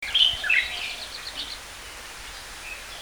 Songs
6 May 2012 Po Toi
A very poor recording, but again all the syllables are similar.